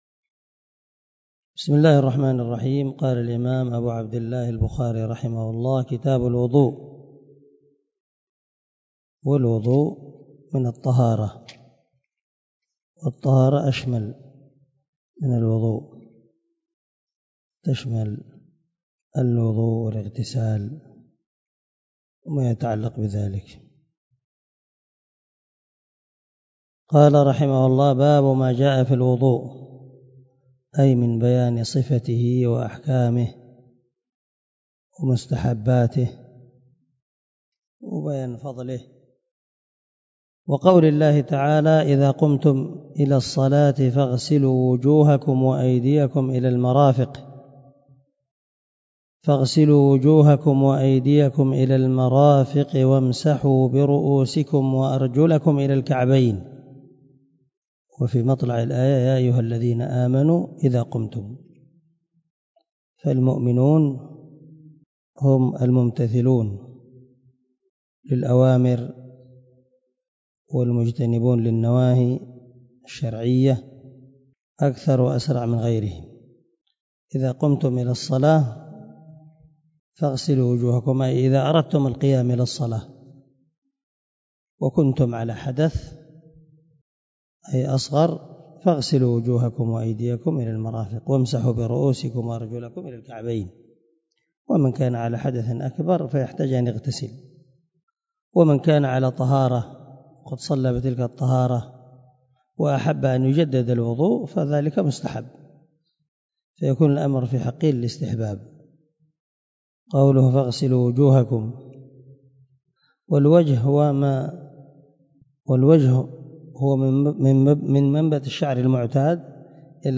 125الدرس 1 من شرح كتاب الوضوء باب ما جاء في الوضوء من صحيح البخاري